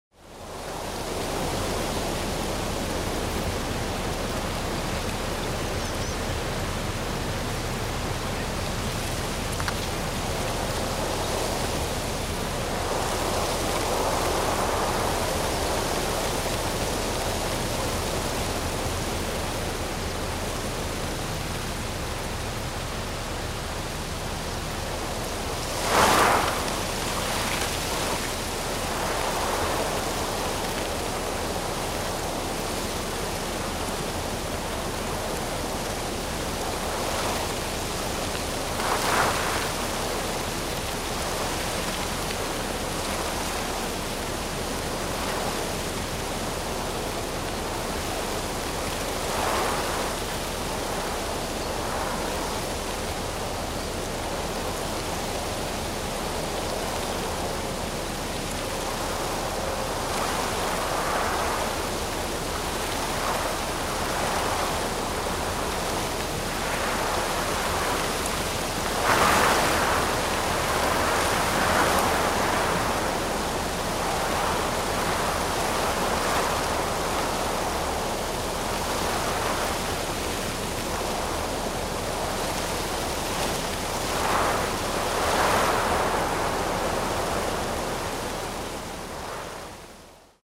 Звуки деревьев
Шепот ветра сквозь деревья